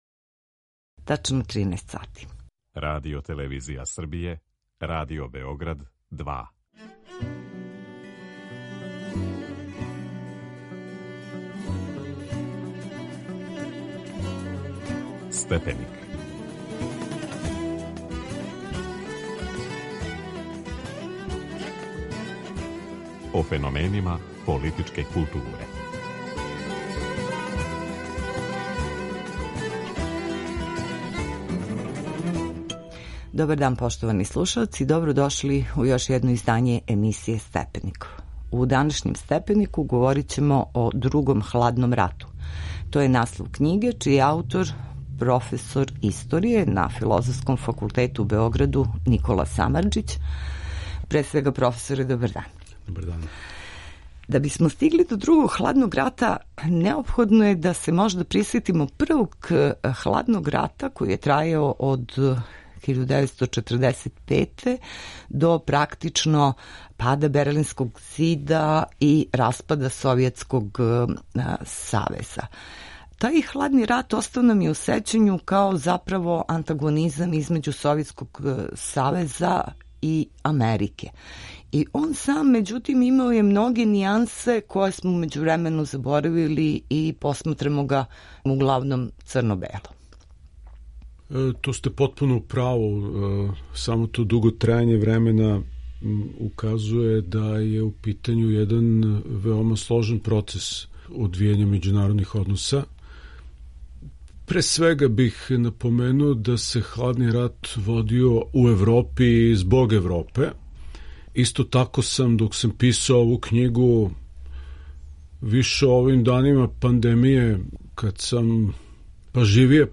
Гост: др Никола Самарџић